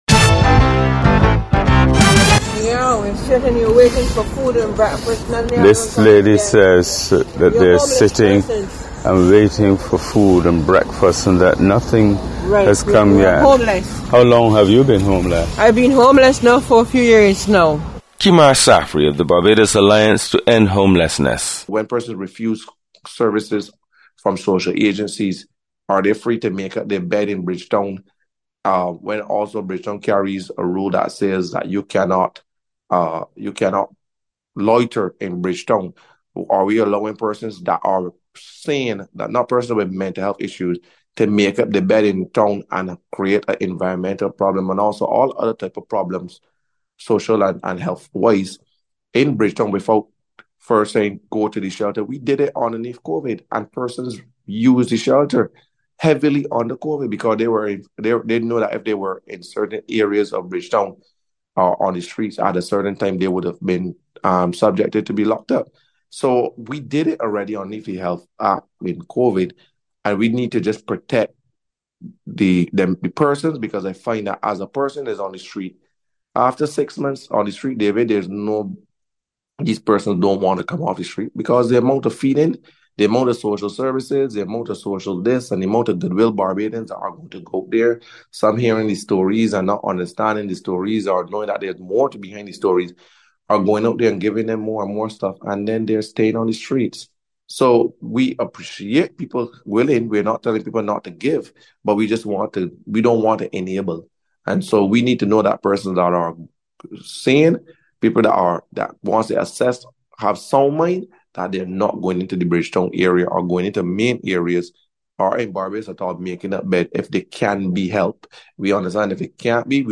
A conversation on the rise in homelessness in Barbados